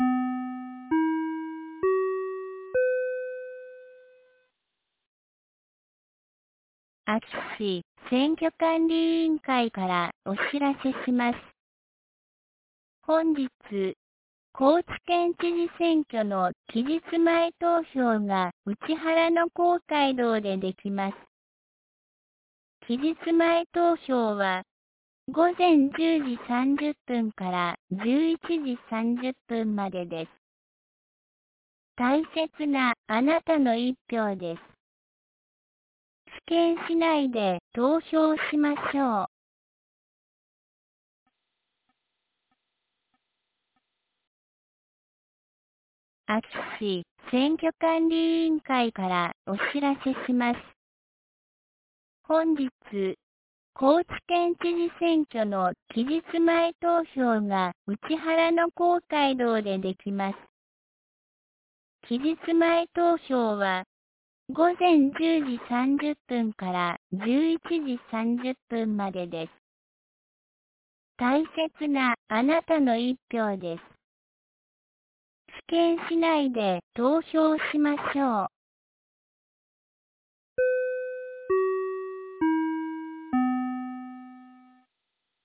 2023年11月22日 09時01分に、安芸市より井ノ口へ放送がありました。